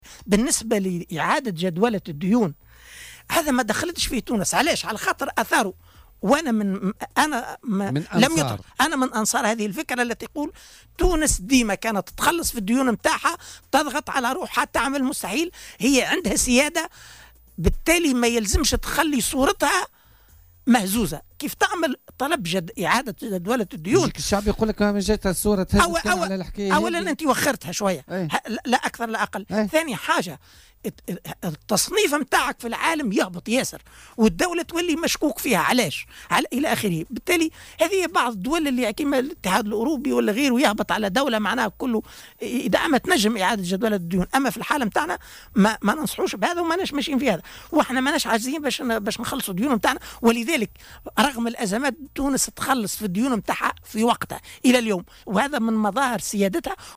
وأضاف العريض خلال استضافته اليوم في "بوليتيكا"، أن بلادنا حفاظا على سيادتها تقوم بخلاص الديون في أجالها، مستبعدا اللجوء إلى إعادة جدولة الديون لأنها ستهزّ صورة تونس في العالم وتؤدي إلى تراجع تصنيفها عالميا.